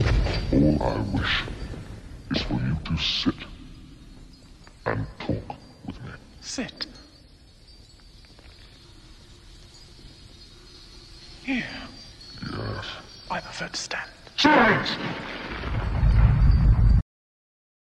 For all experiments, I used a sound clip from one of my favorite movies “Legend” where Tim Curry plays the devil, and Tom Cruise and Mia Sara are the main characters fighting him.
Here is a sound where we play the grains back at 0.77 speed (1.0 / 1.3).
out_c_low.mp3